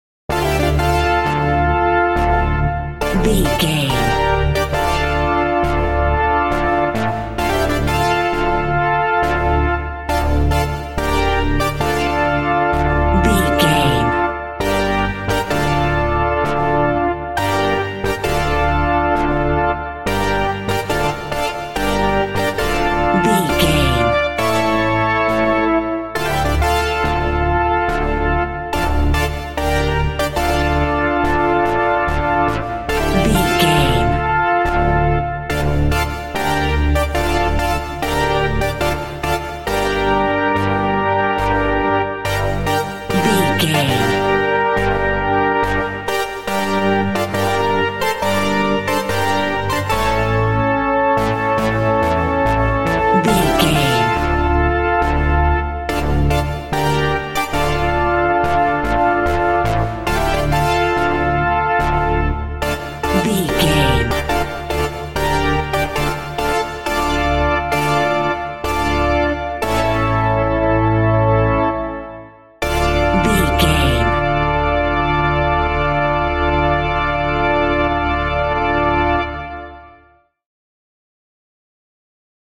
Eighties Synth Thriller Music Cue.
Aeolian/Minor
tension
ominous
eerie
Horror synth
Horror Ambience
synthesizer